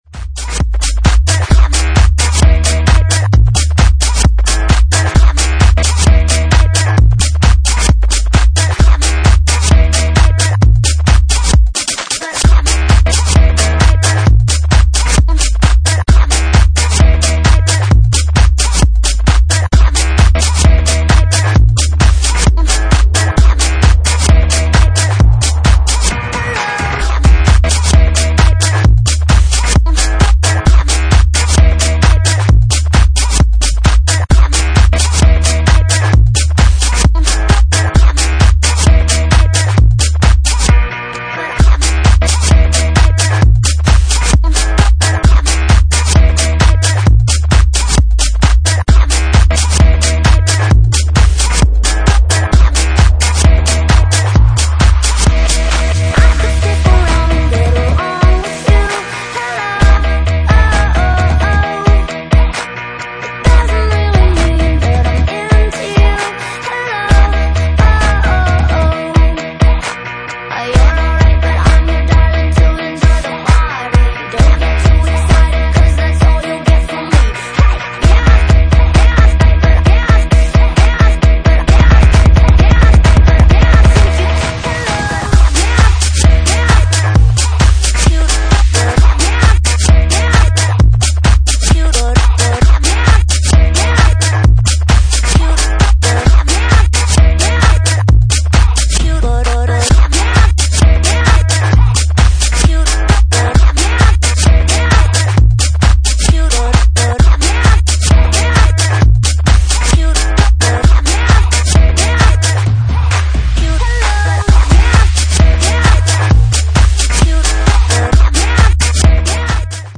Genre:Jacking House
Jacking House at 133 bpm